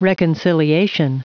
Prononciation du mot reconciliation en anglais (fichier audio)
Prononciation du mot : reconciliation